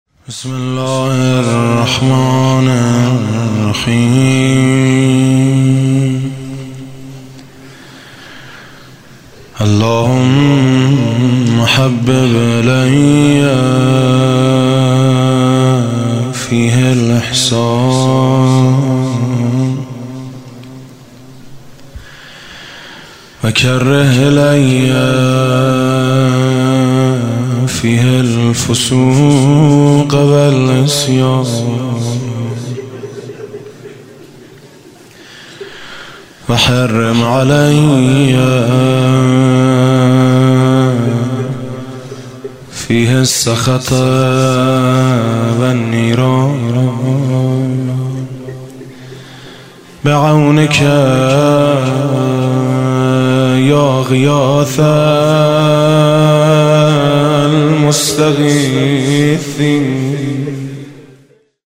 Doa-11Ramazan.mp3